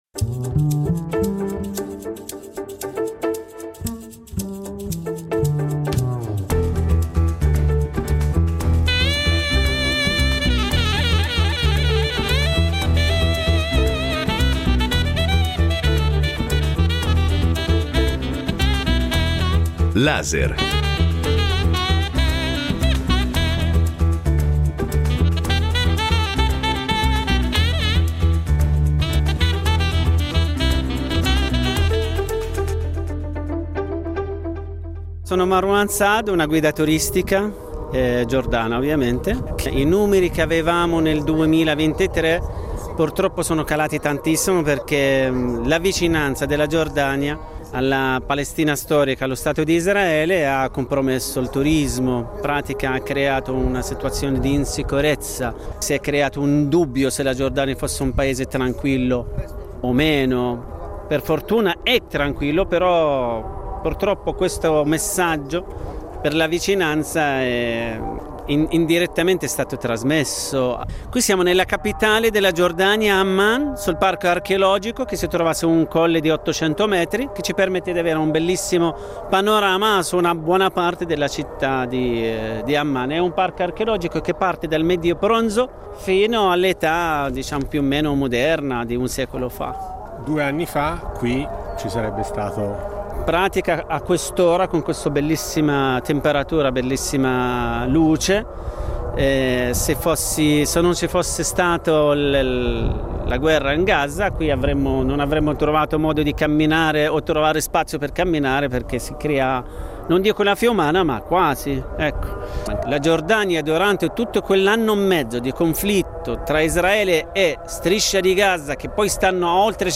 In questo reportage che ci ha portati dalla capitale Amman all’entità economica autonoma di Aqaba, affacciata sul Mar Rosso, abbiamo cercato di comprendere come ha fatto la Giordania a mantenere una stabilità invidiabile e una buona condizione economica interna considerando che confina con aree di grande crisi come la Siria, il Libano, l’Iraq e la Palestina.